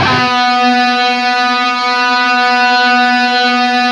LEAD B 2 LP.wav